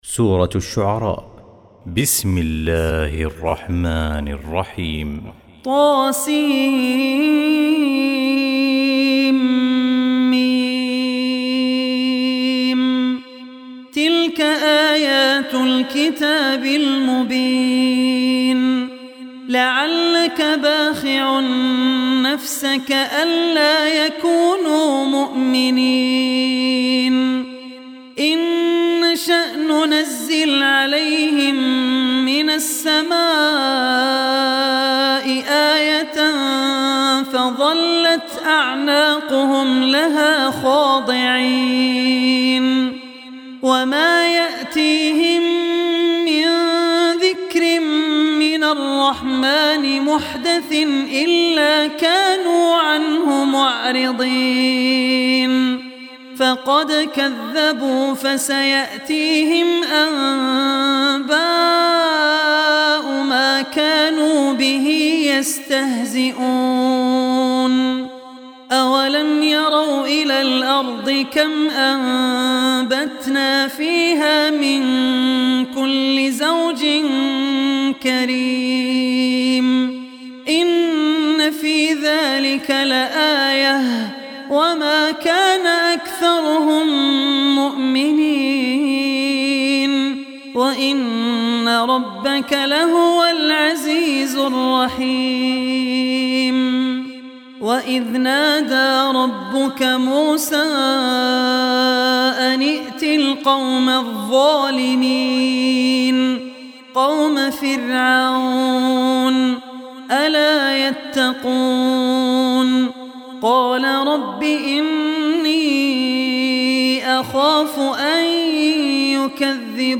Surah Ash-Shuara Recitation